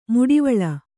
♪ muḍivaḷa